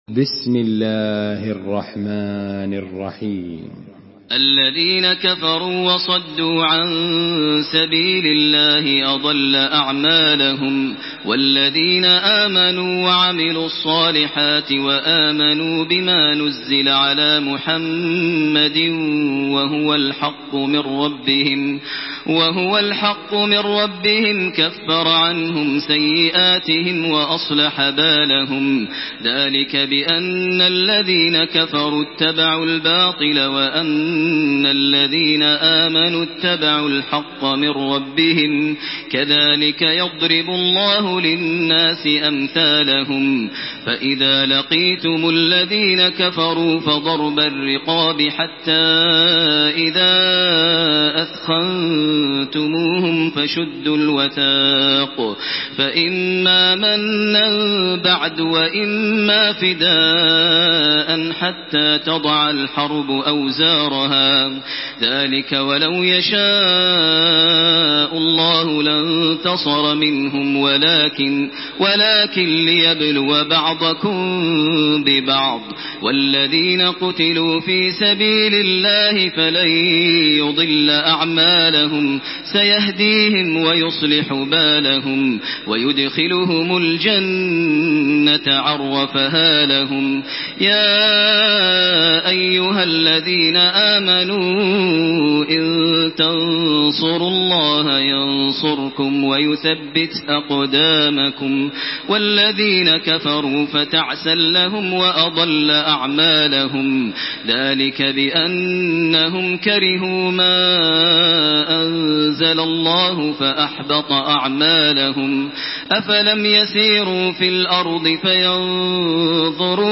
Surah Muhammad MP3 by Makkah Taraweeh 1428 in Hafs An Asim narration.
Murattal